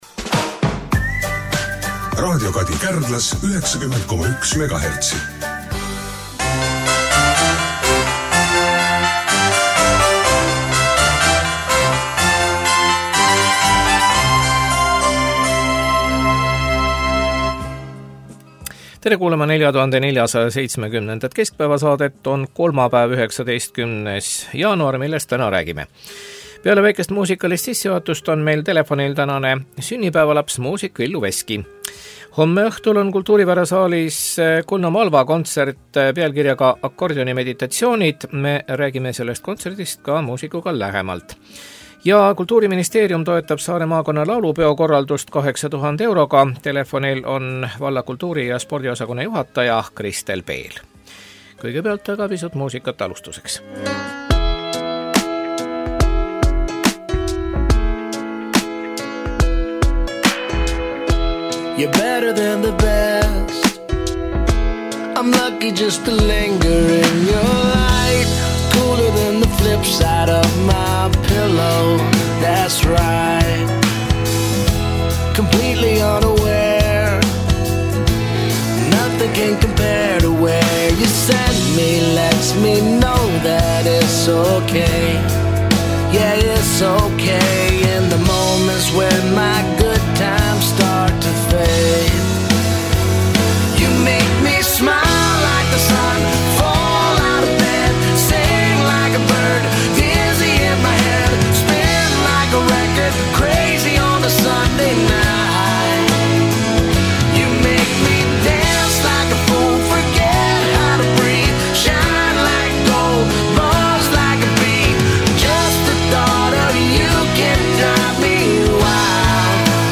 Telefonil on tänane sünnipäevalaps muusik Villu Veski.